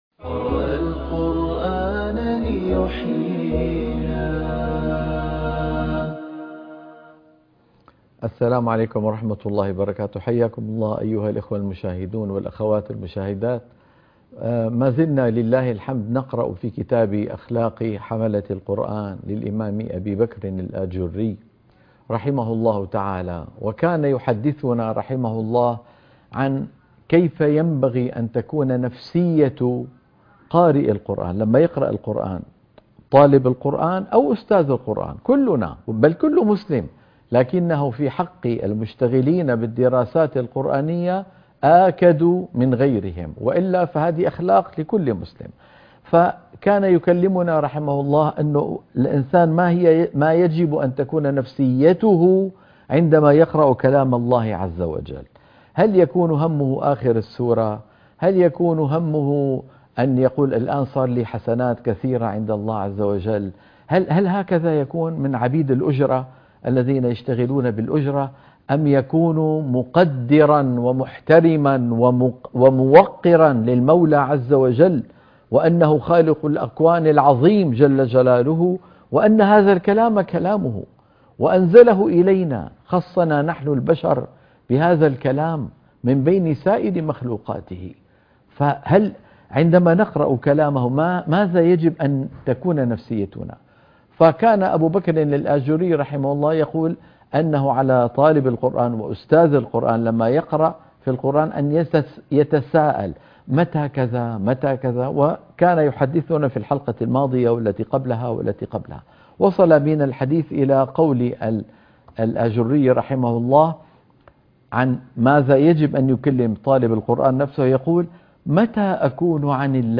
قراءة كتاب أخلاق حملة القرآن